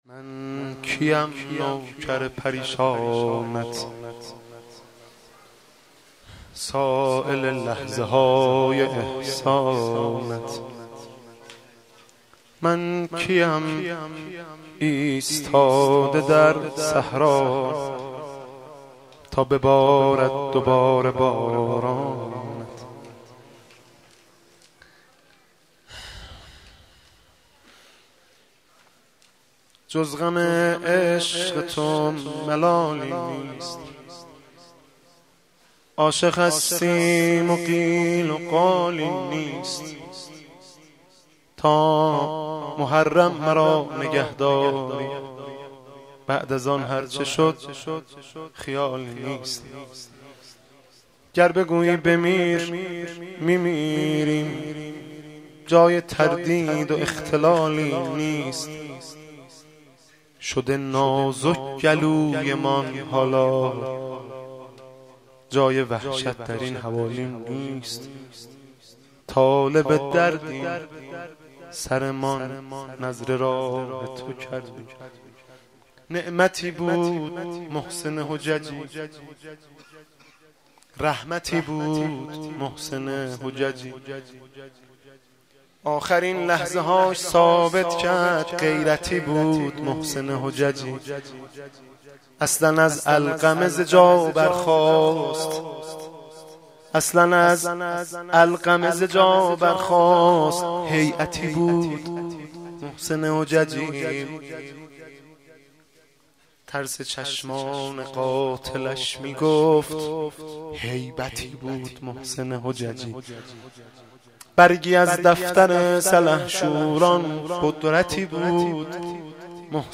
شعر خوانی زیبای مهدی رسولی برای شهید مدافع حرم محسن حججی.